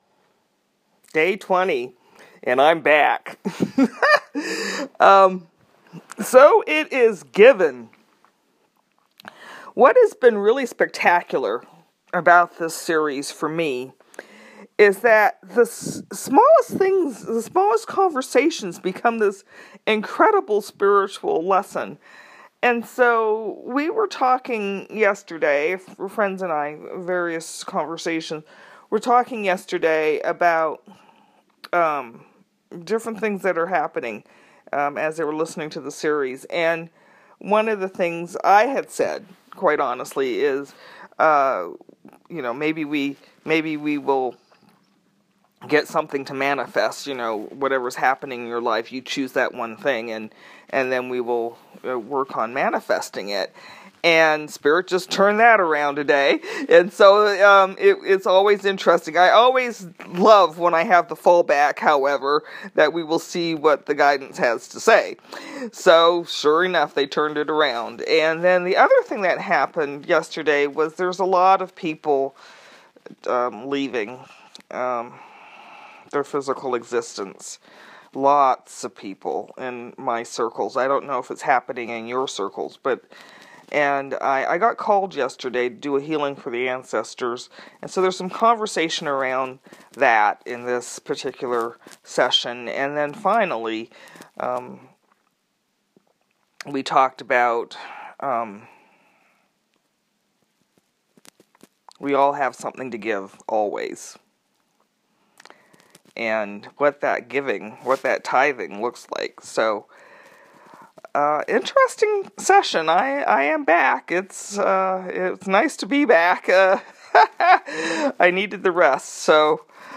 Each Channel is RECORDED in the morning and then POSTED on the 36 CHANNELS IN 36 DAYS web page later in the day.